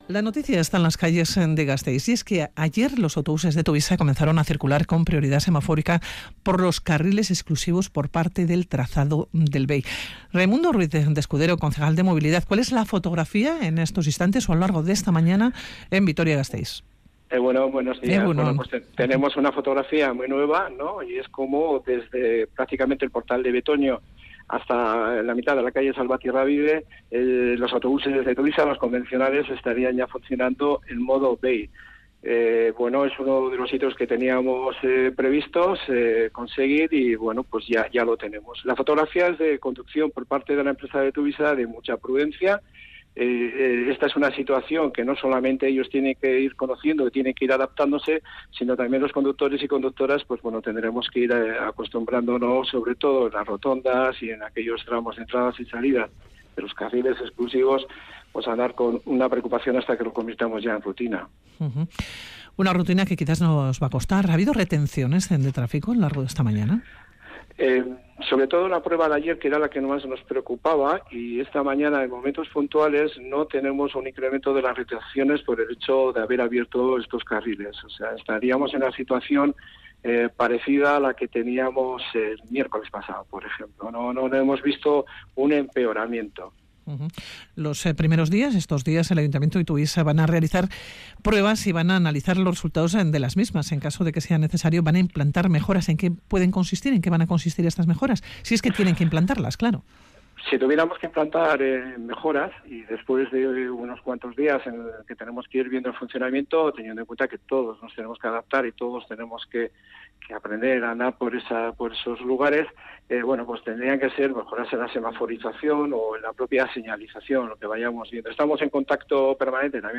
Audio: Nos desplazamos a una rotonda con bastante flujo circulatorio (la del Verode, Ariznabarra). El concejal de Movilidad, Raimundo Ruiz de Escudero, nos explica cómo se ha estructurado el plan.